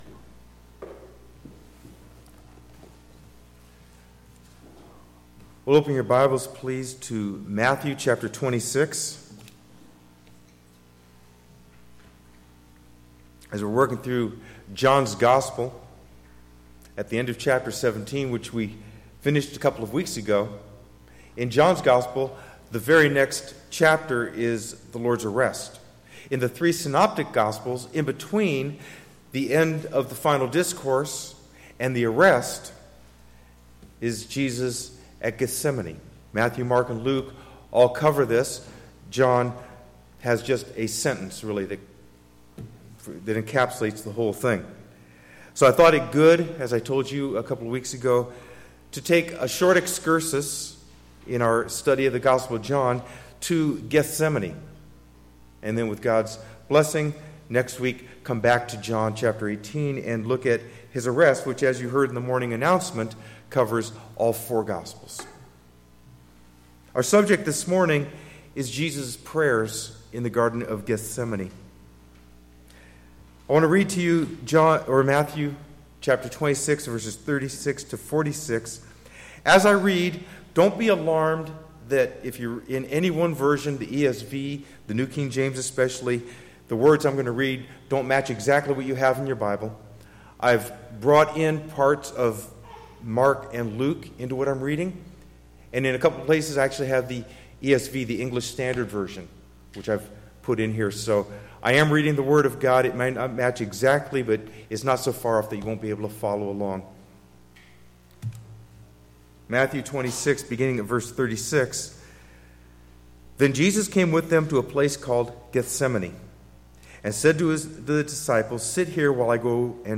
Silicon Valley Reformed Baptist Church in Sunnyvale, California
Curious about something taught in this sermon?